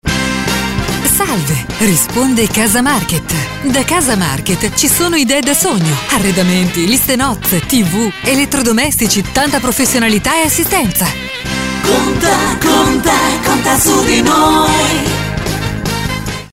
segreterie per risponditori telefonici
Risponditore Casa Market